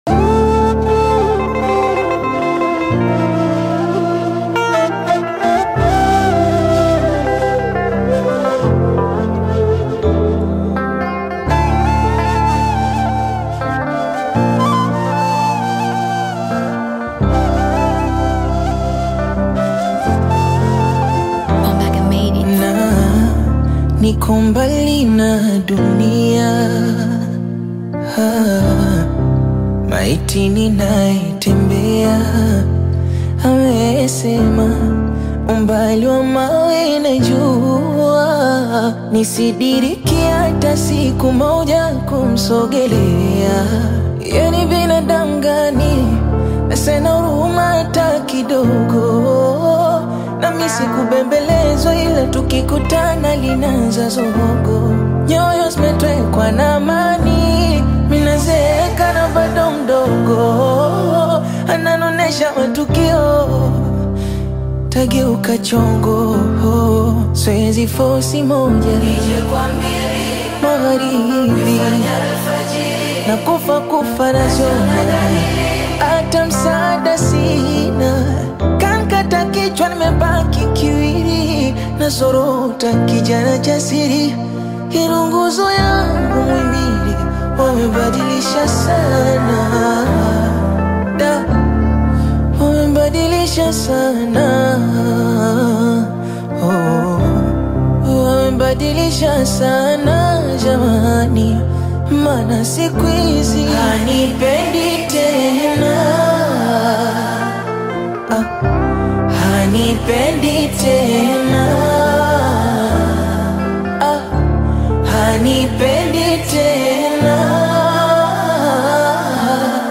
stripped-down Bongo Flava/acoustic rendition
Genre: Bongo Flava